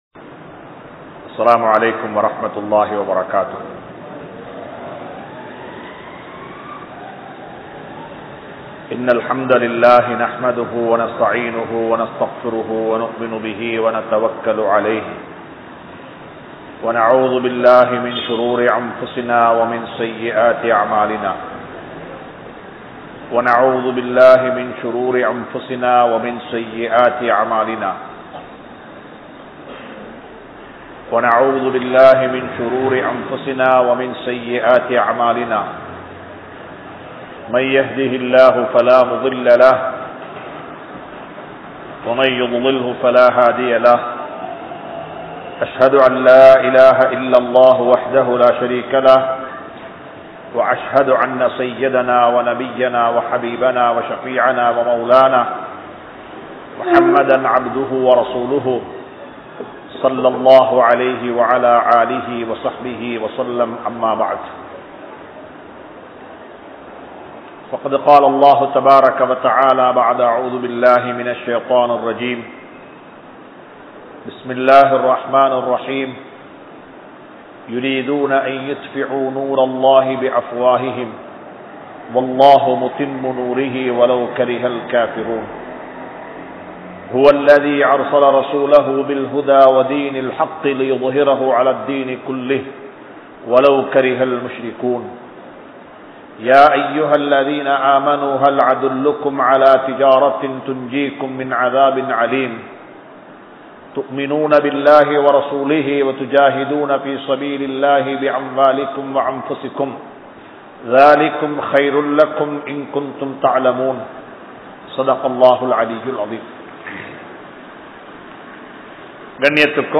Social Service in Islam | Audio Bayans | All Ceylon Muslim Youth Community | Addalaichenai